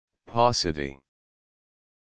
paucity.mp3